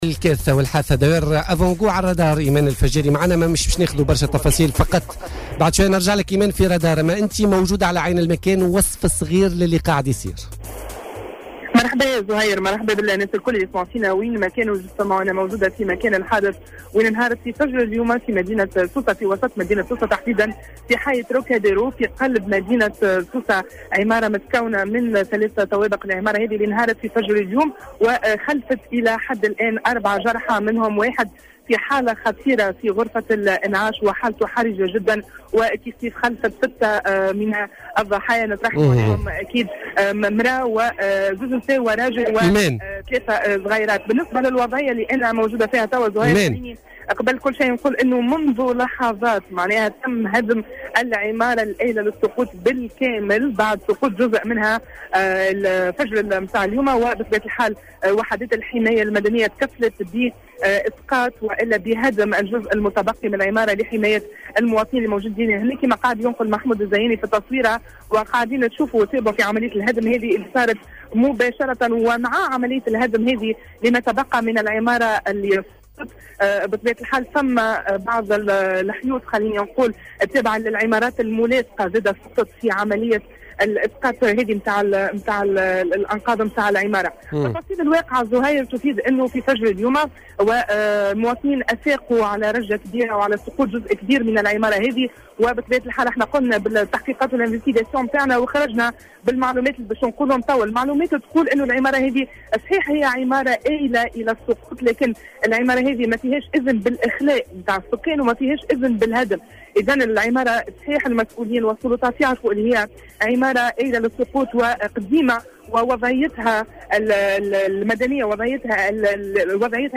تحوّل فريق الرادار اليوم الخميس إلى موقع حادثة انهيار بناية سكنية في منطقة "تروكاديرو" وسط مدينة سوسة.